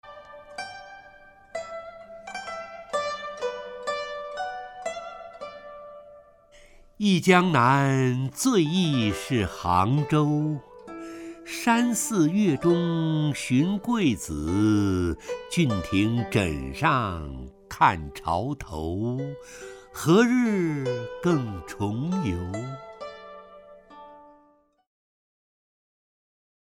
陈醇朗诵：《忆江南·江南忆》(（唐）白居易) （唐）白居易 名家朗诵欣赏陈醇 语文PLUS